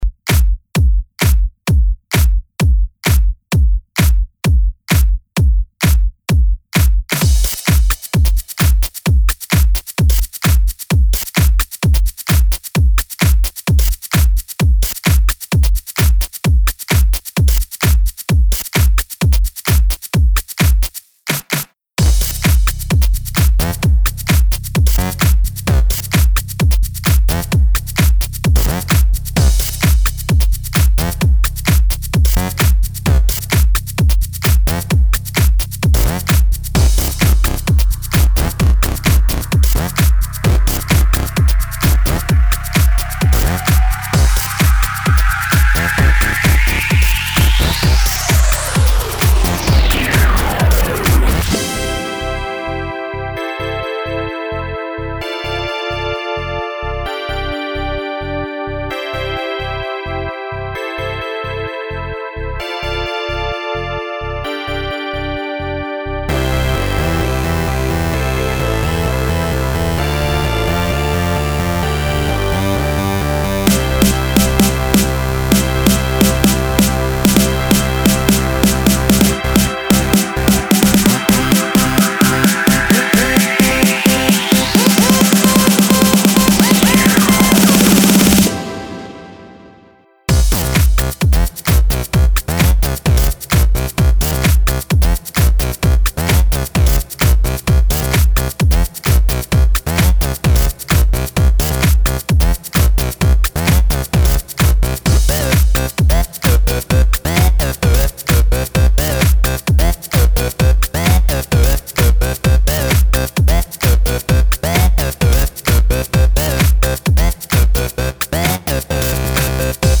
Может тогда люди перестанут себе усложнять жизнь?))=)Как и просили делать уклон на клубную тематику.